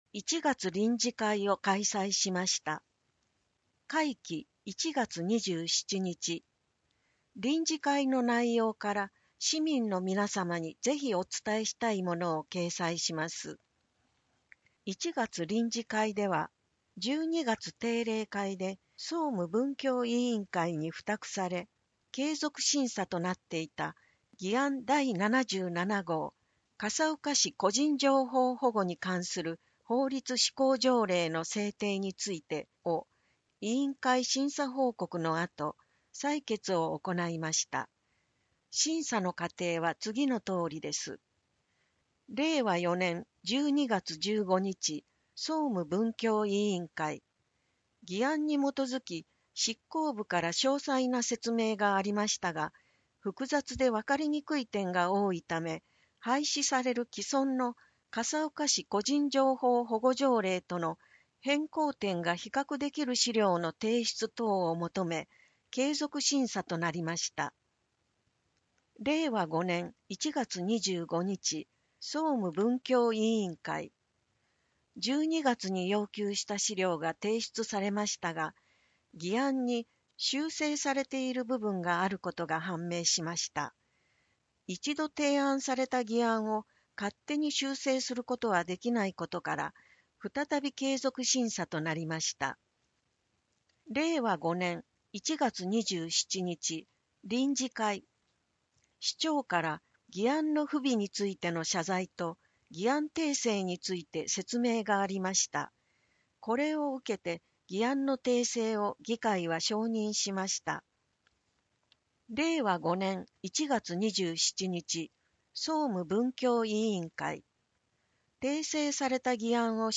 市議会だより176号（音訳版）
（音声データ提供 笠岡音訳の会） 76分20秒